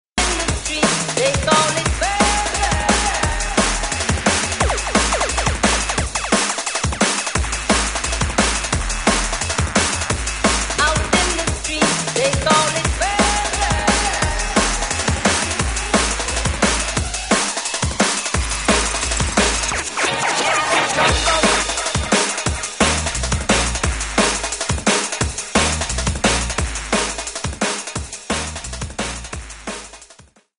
TOP > Vocal Track